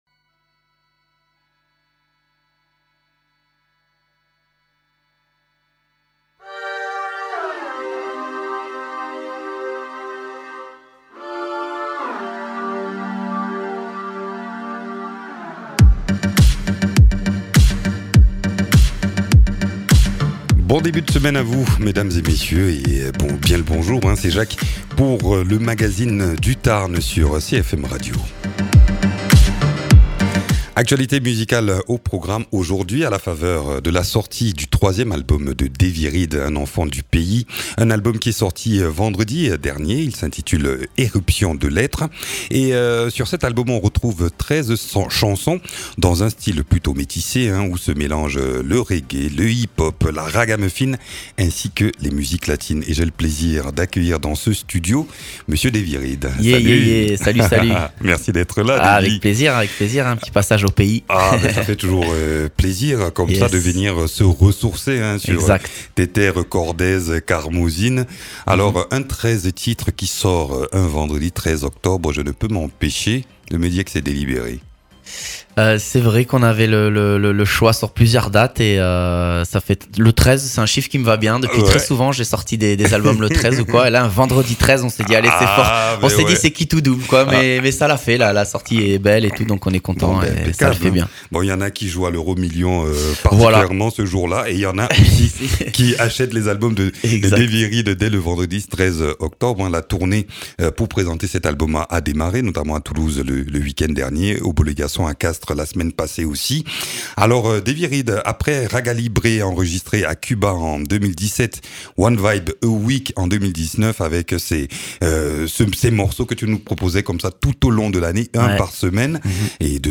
auteur-compositeur-interprète.